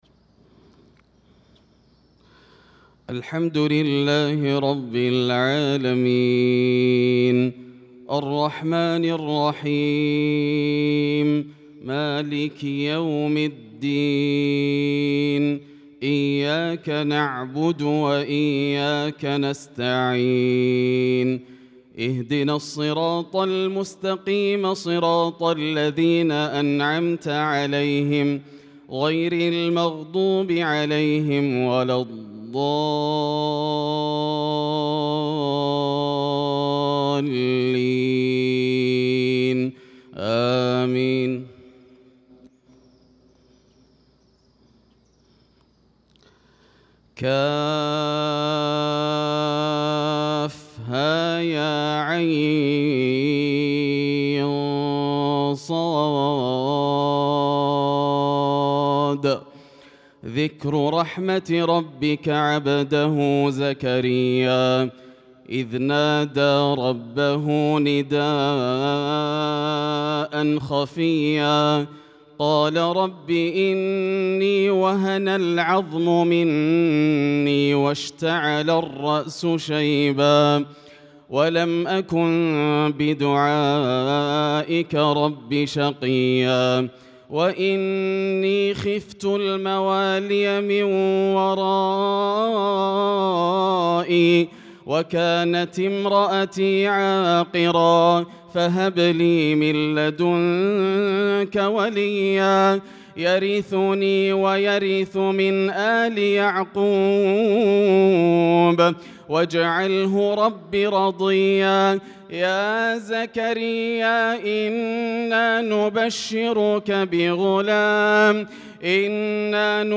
صلاة الفجر للقارئ ياسر الدوسري 11 شوال 1445 هـ
تِلَاوَات الْحَرَمَيْن .